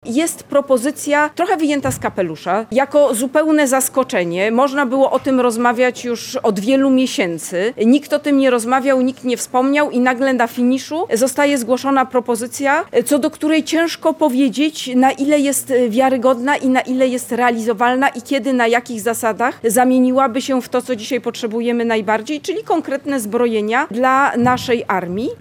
– Polska 2050 mówi jasno – unijny SAFE powinien zostać podpisany i zrealizowany – podkreślała w Kraśniku szefowa tego ugrupowania, ministra funduszy i polityki regionalnej Katarzyna Pełczyńska-Nałęcz. Prezydencką propozycję „SAFE 0 procent” określiła mianem „wyjętej z kapelusza”.